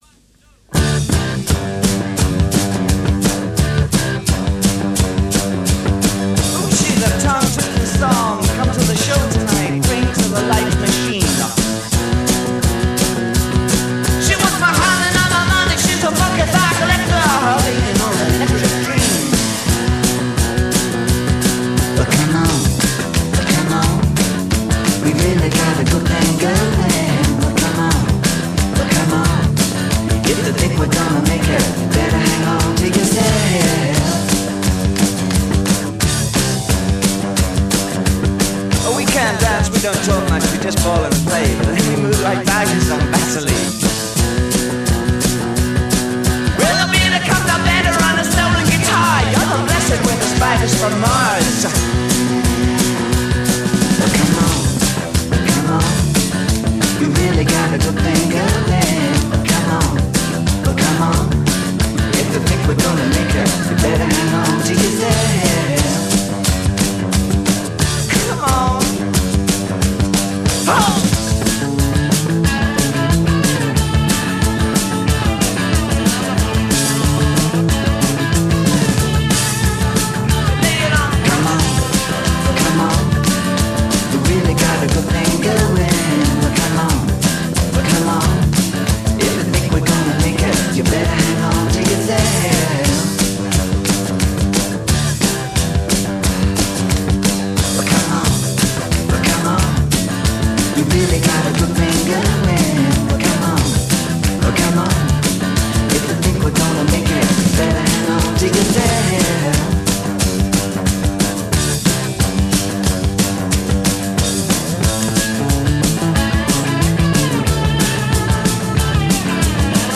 Glam Rock, Live Rock